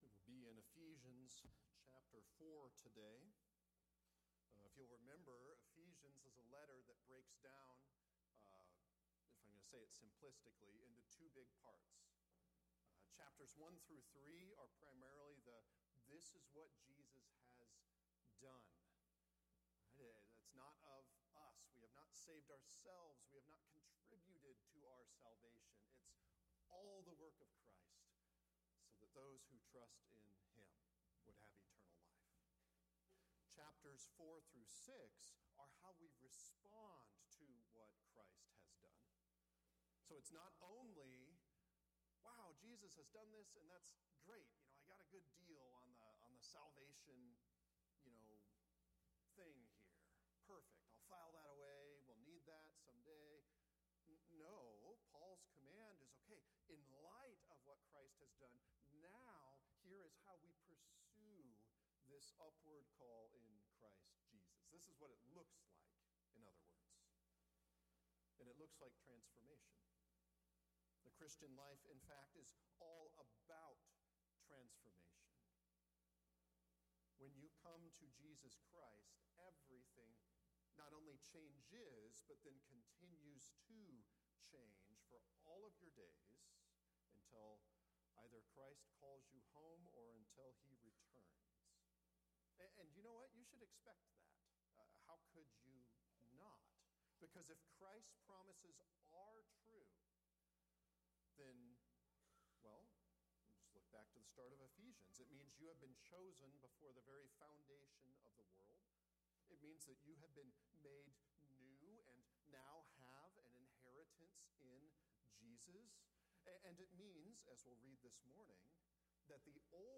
Ephesians 4:17-24 Were and Are – Sermons